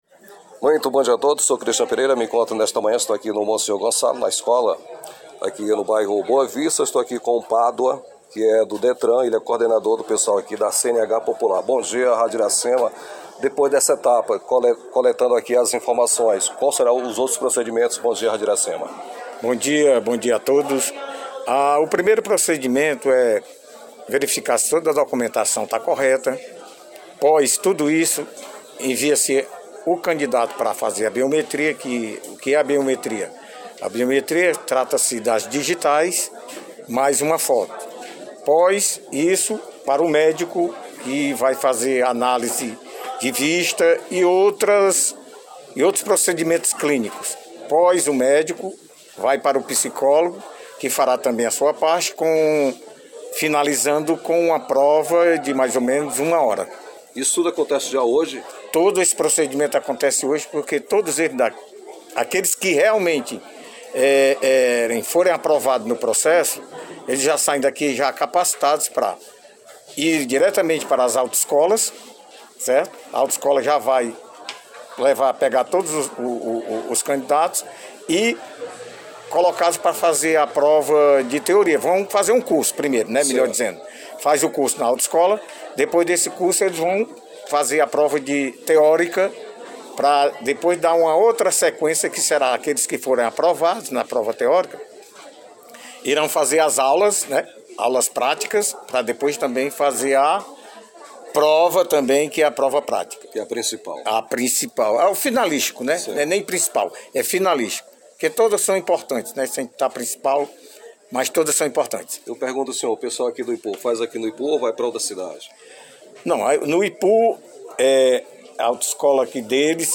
Com entrevista com o repórter